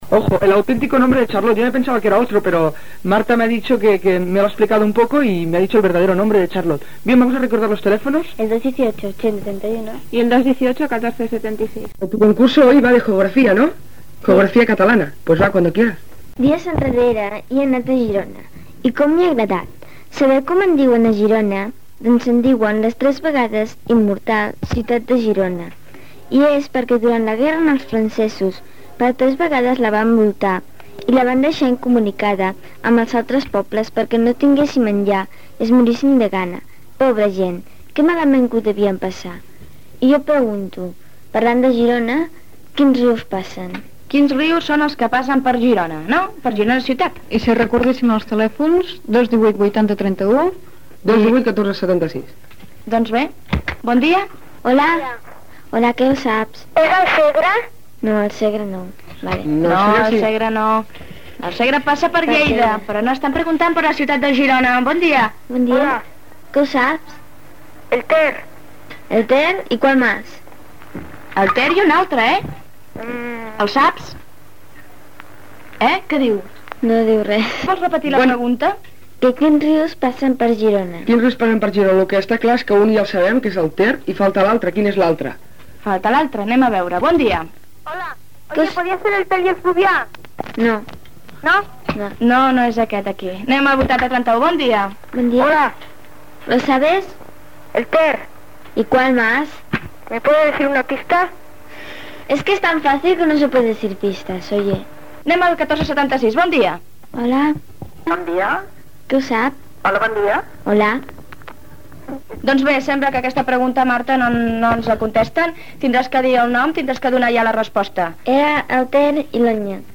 Telèfons, pregunta sobre Girona i trucades de l'audiència.
Infantil-juvenil